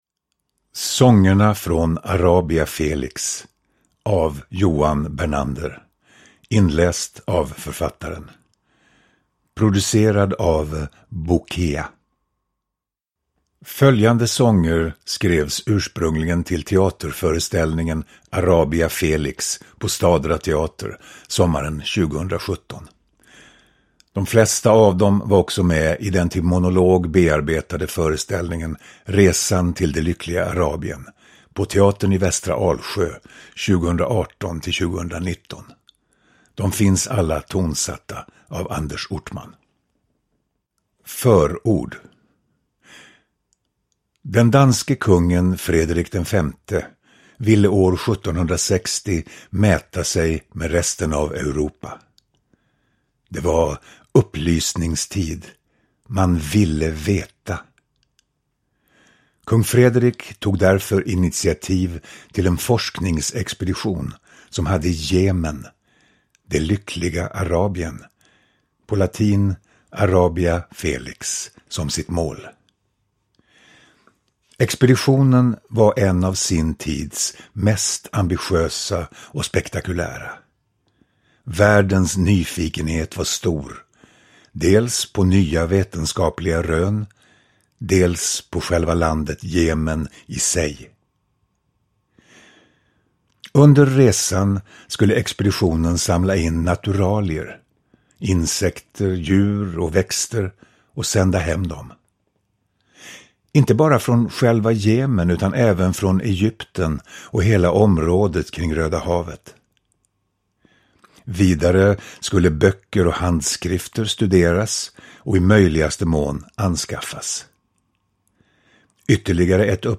Lyrik
Ljudbok